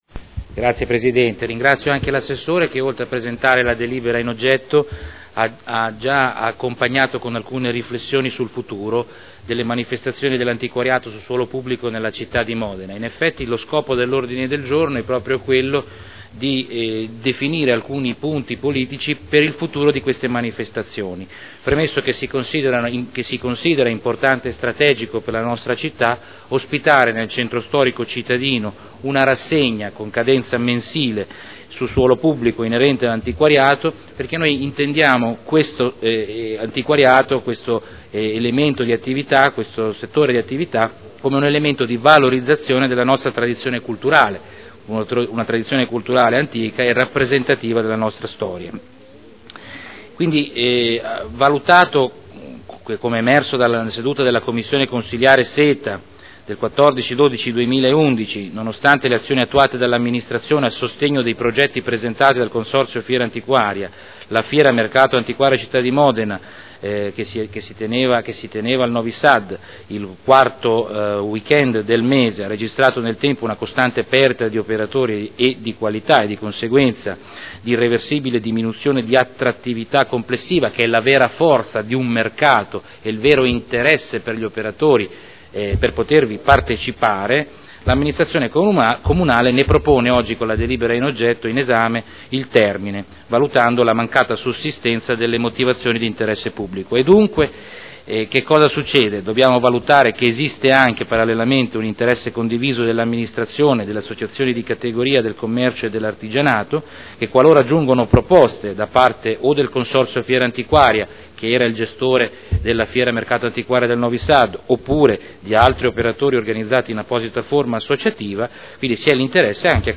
Salvatore Cotrino — Sito Audio Consiglio Comunale
Seduta del 22/12/2011. Introduce nuovo ordine del giorno sulla proposta di deliberazione.